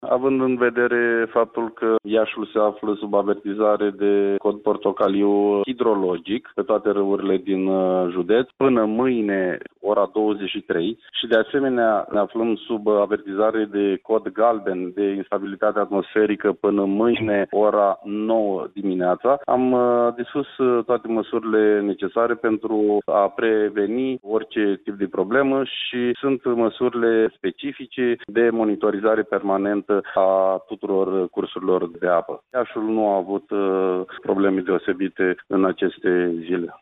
Deocamdată, nu au fost semnalate probleme în judeţ ca urmare a precipitaţiilor, după cum a declarat prefectul de Iaşi, Marian Şerbescu: